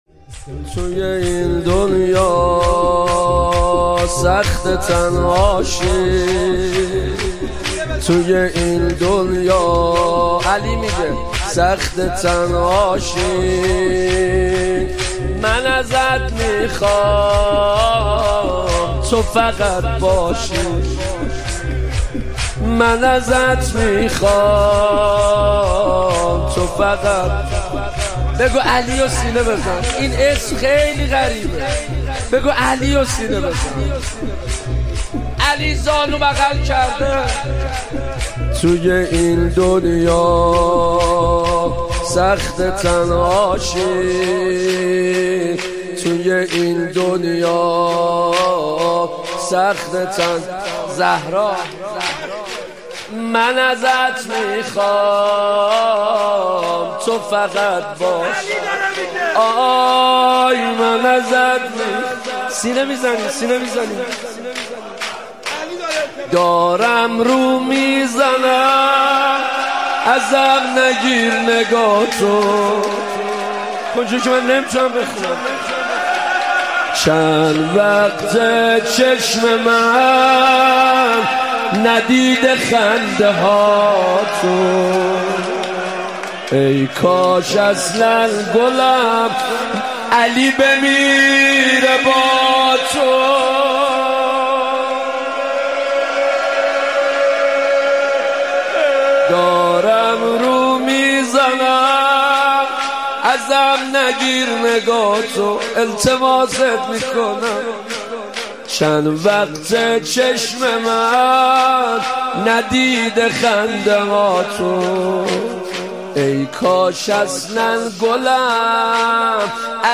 مداحی
شب سوم ایام فاطمیه اول 1397
شور